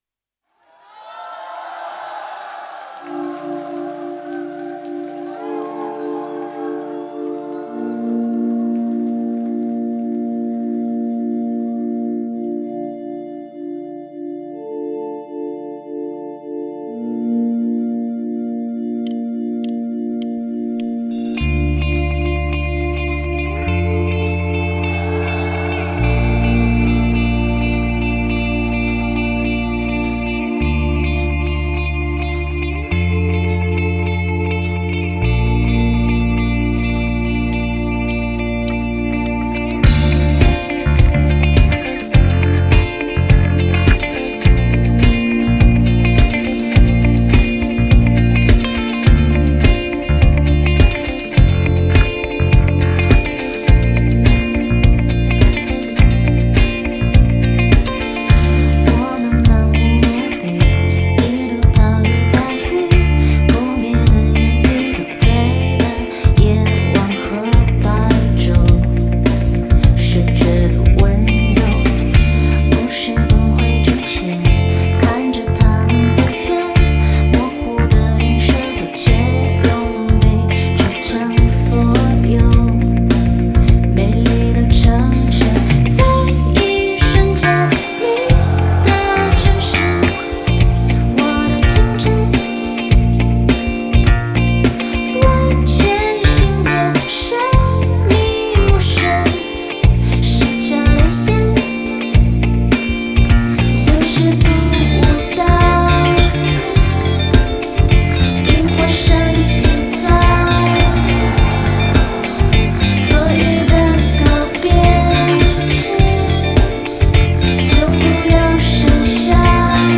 爵士鼓